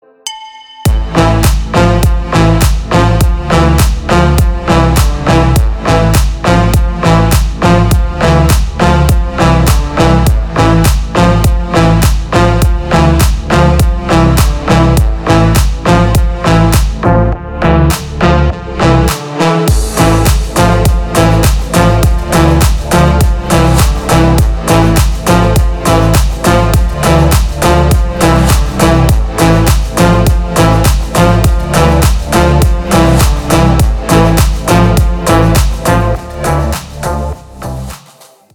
• Качество: 320, Stereo
dance
Electronic
без слов
качающие
клубняк
Стиль: deep house